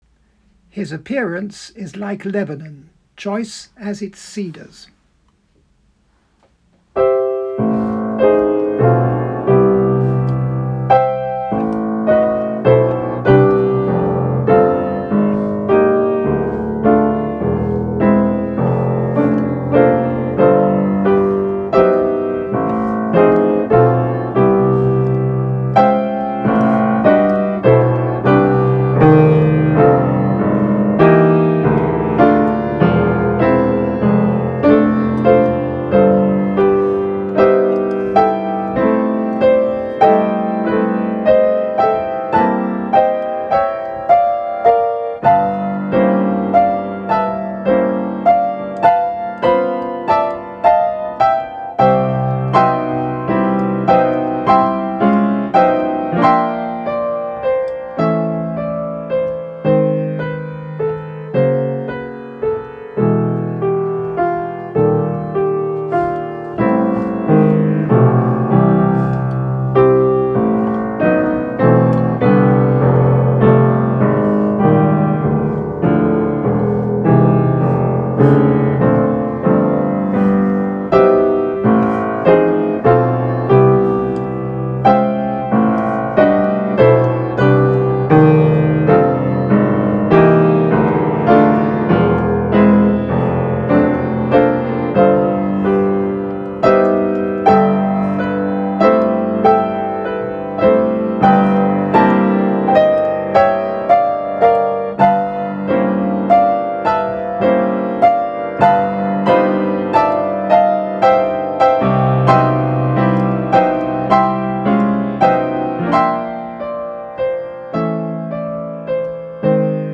Twelve pieces for piano (Grade four to seven).